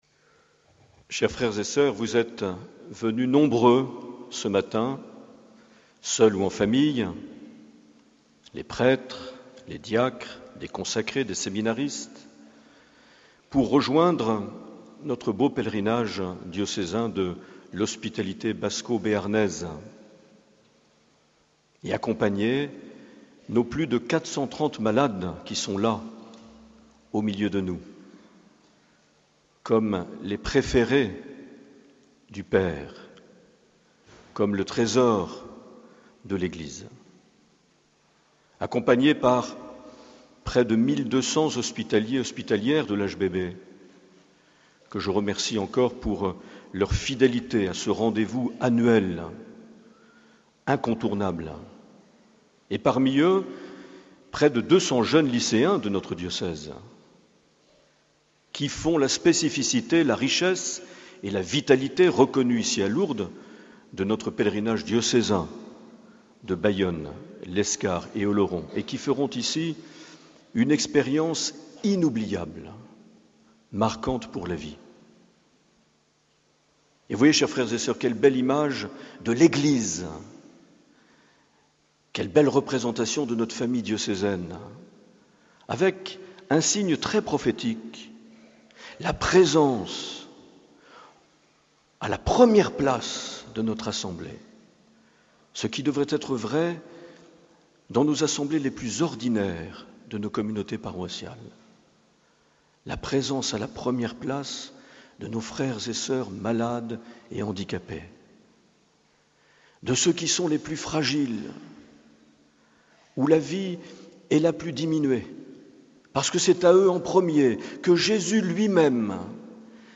23 septembre 2013 - Lourdes Sainte Bernadette - Messe du pèlerinage diocésain
Accueil \ Emissions \ Vie de l’Eglise \ Evêque \ Les Homélies \ 23 septembre 2013 - Lourdes Sainte Bernadette - Messe du pèlerinage (...)
Une émission présentée par Monseigneur Marc Aillet